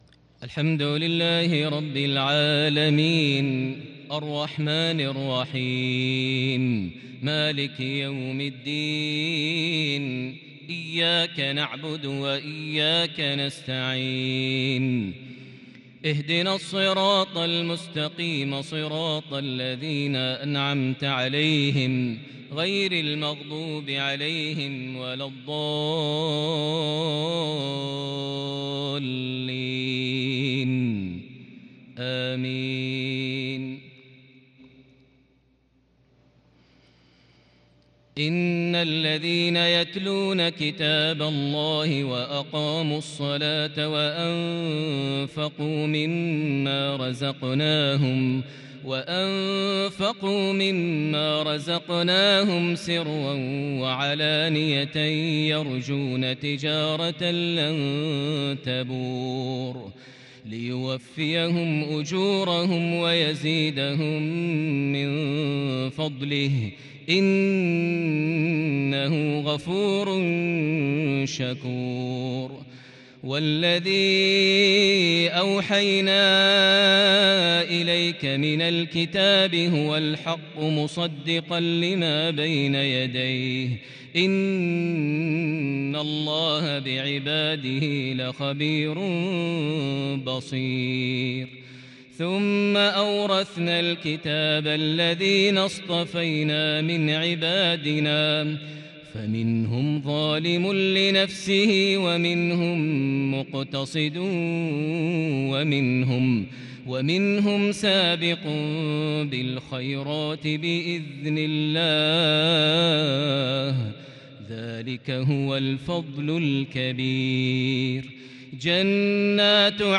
عشائية بديعة بترتيل أسطوري بالكرد من سورة فاطر (29-41) | الأربعاء 13 ذو القعدة 1442هـ > 1442 هـ > الفروض - تلاوات ماهر المعيقلي